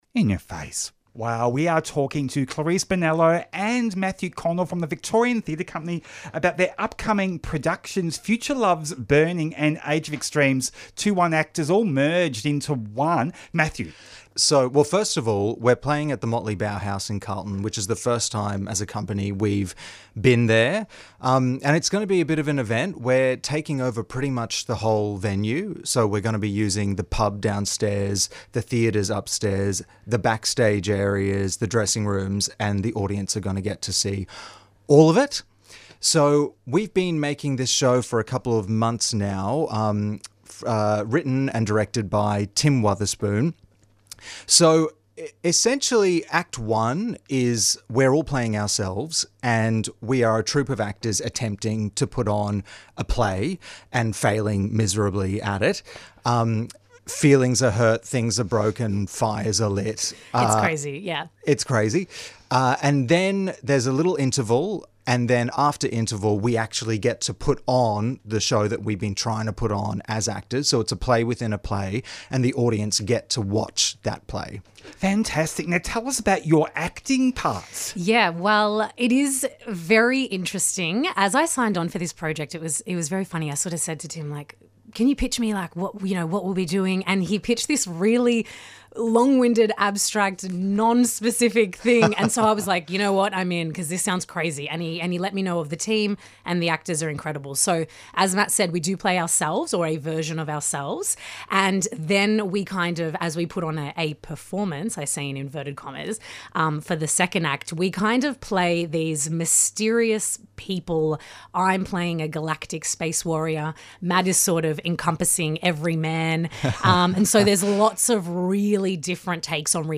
Motley Bauhaus, May 7 to 23. home | VTC Tweet In Ya Face Friday 4:00pm to 5:00pm Explores LGBTIQA+ issues with interviews, music and commentary.